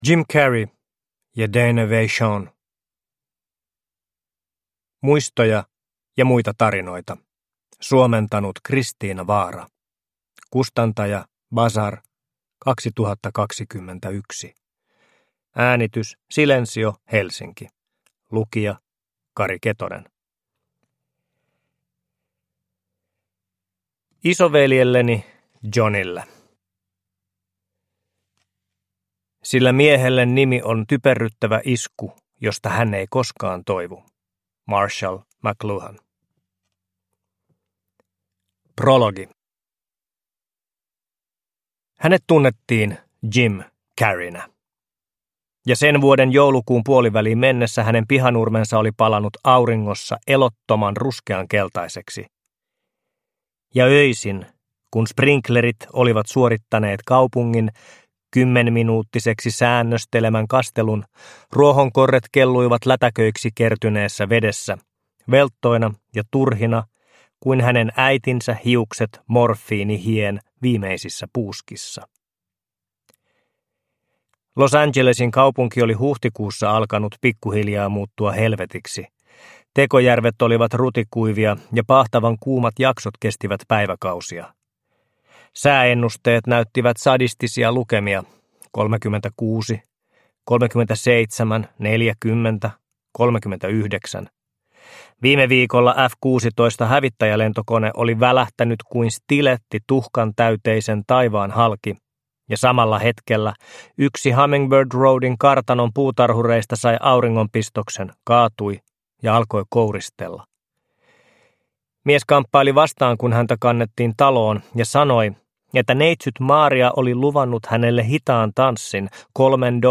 Muistoja ja muita tarinoita – Ljudbok – Laddas ner